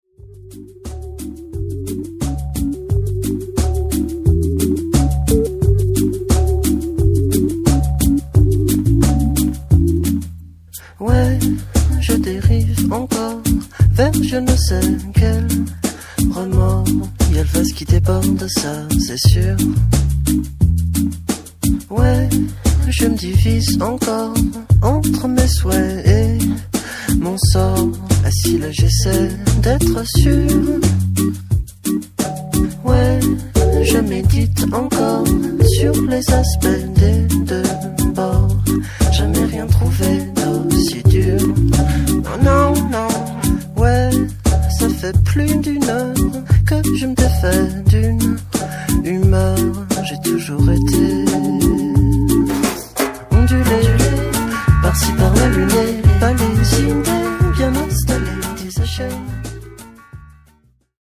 classic and contemporary French chanson